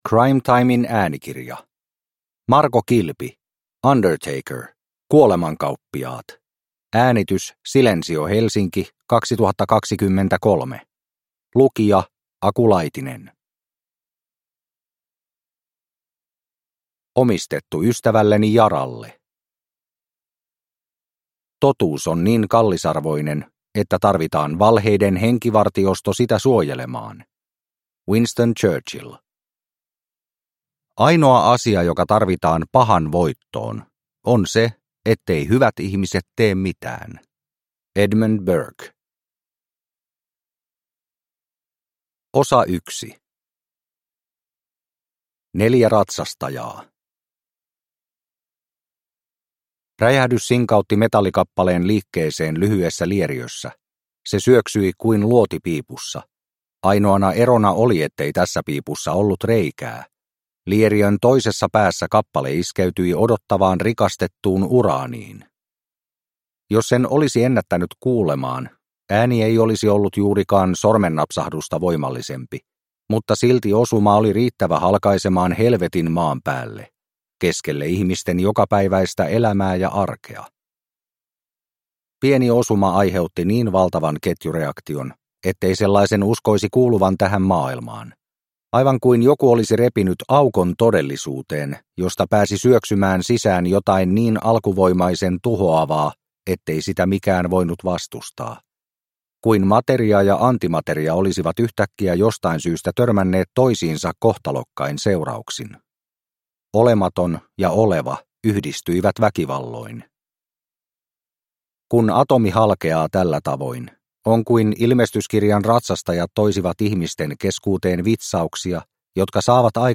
Undertaker – Kuolemankauppiaat – Ljudbok – Laddas ner